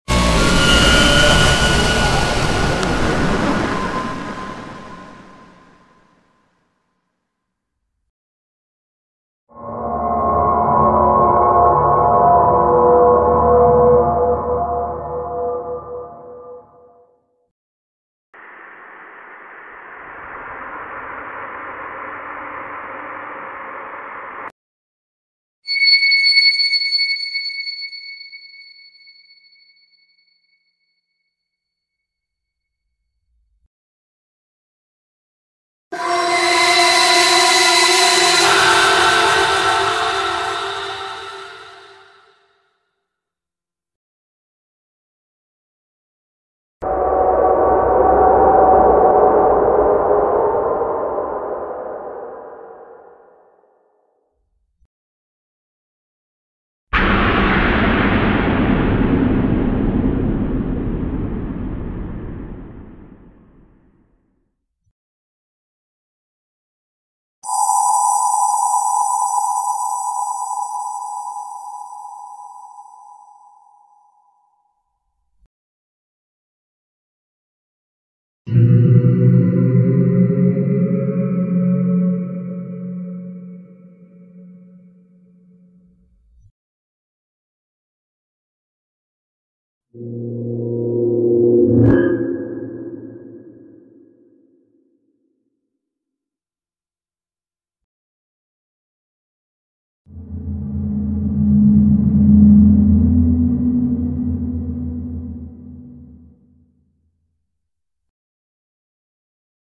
Effetti Sonori: Suoni Horror / Sound Effects Free Download